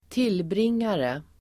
Uttal: [²t'il:bring:are]